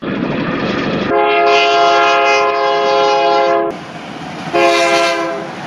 دانلود آهنگ بوق 9 از افکت صوتی حمل و نقل
دانلود صدای بوق 9 از ساعد نیوز با لینک مستقیم و کیفیت بالا
جلوه های صوتی
برچسب: دانلود آهنگ های افکت صوتی حمل و نقل دانلود آلبوم صدای بوق ماشین از افکت صوتی حمل و نقل